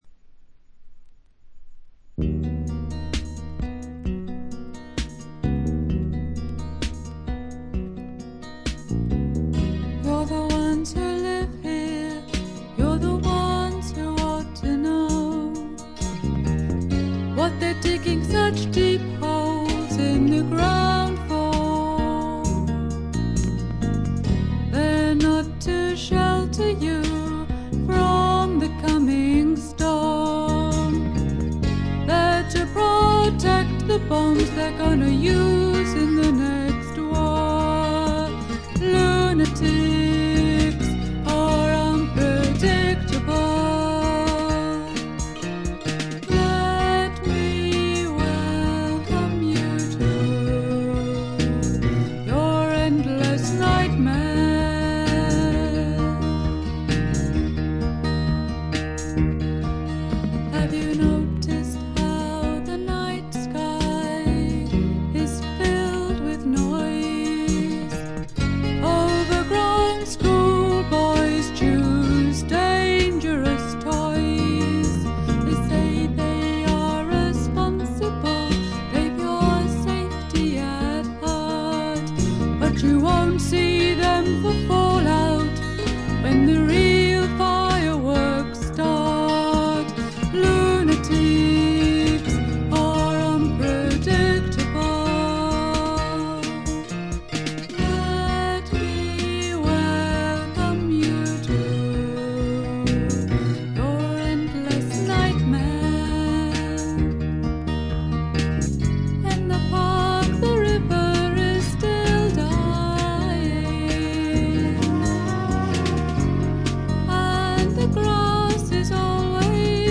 ホーム > レコード：英国 SSW / フォークロック
ところどころで軽いチリプチ程度。
少しざらついた美声がとても心地よいです。
試聴曲は現品からの取り込み音源です。